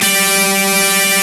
Index of /90_sSampleCDs/Roland L-CDX-01/GTR_Distorted 1/GTR_Power Chords